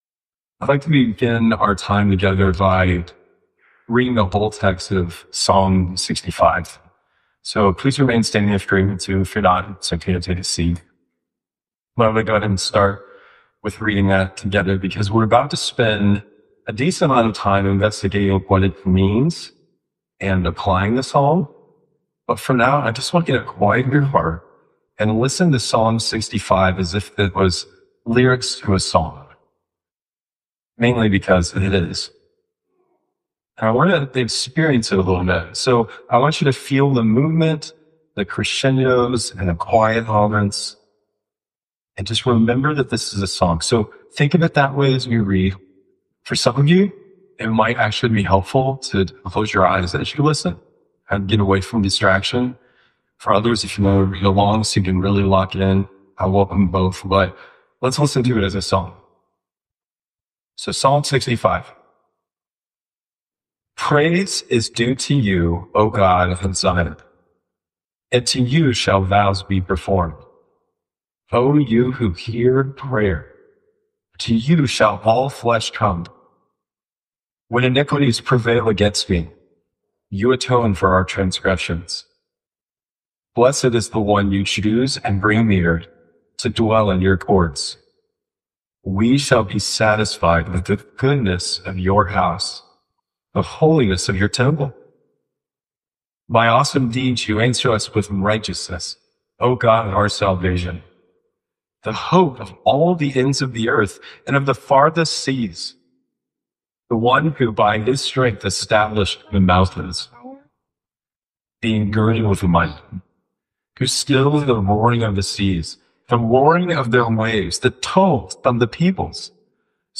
hope-fellowship-worship-service-august-3-2025.mp3